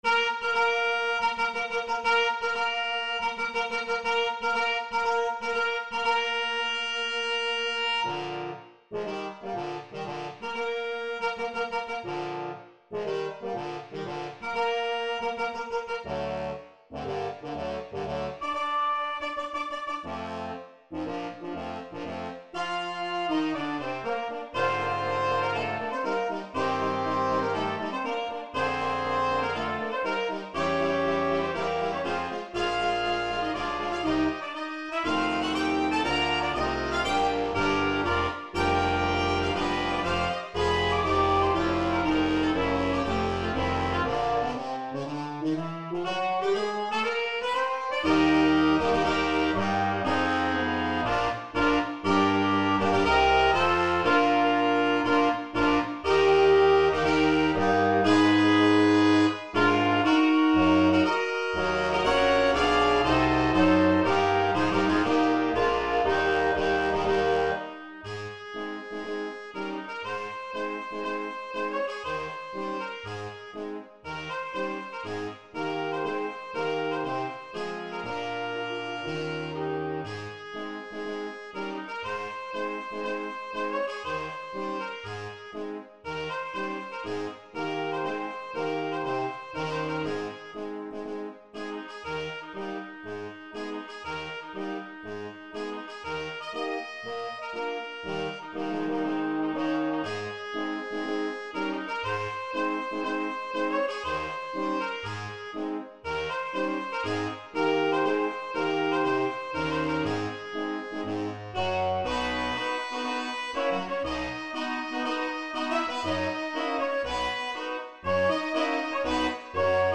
3 Trumpets
2 Horns in F
2 Trombones
Euphonium
Tuba
for Brass Nonett